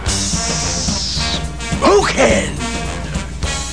Sound effect included!